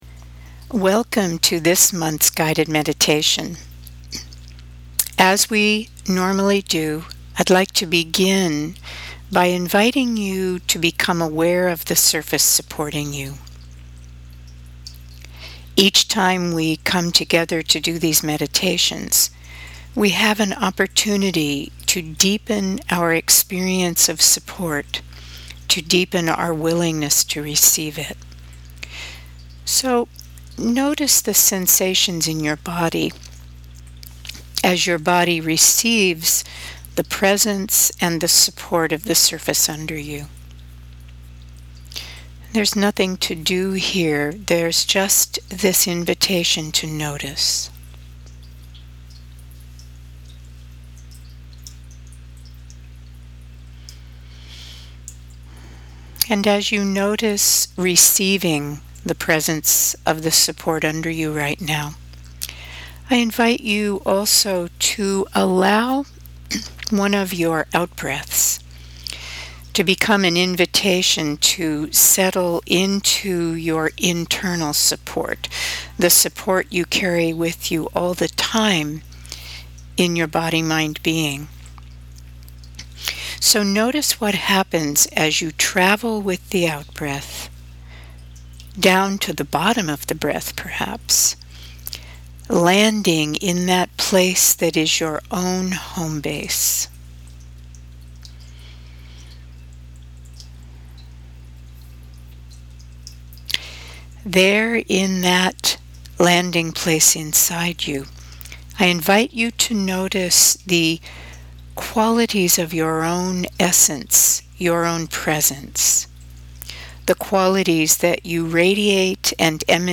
Please remember never to listen to guided audio meditations while driving or using dangerous machinery.